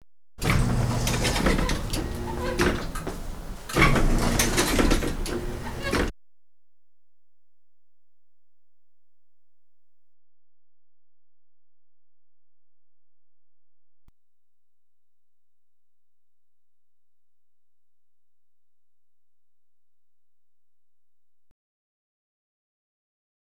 Door Automatic Sensor Open And Close Sound Effect
Download a high-quality door automatic sensor open and close sound effect.
door-automatic-sensor-open-and-close.wav